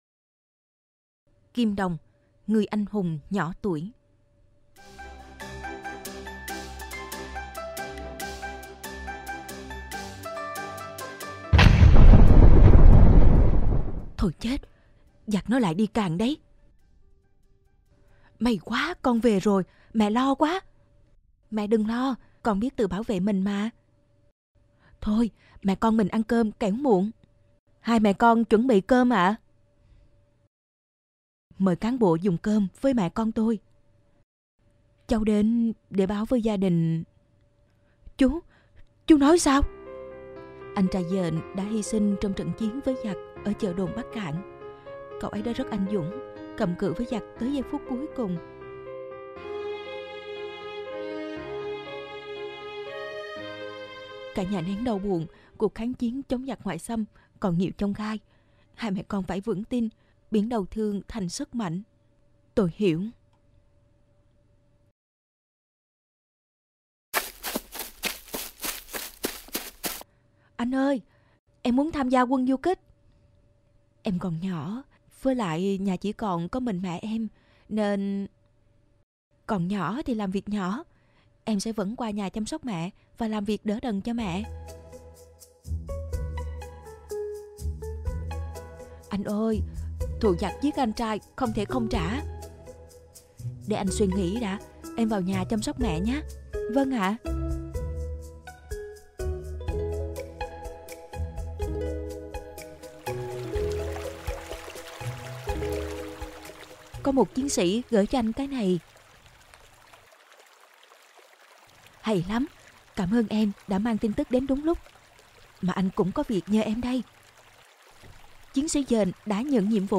Sách nói | Kim Đồng - người anh hùng nhỏ tuổi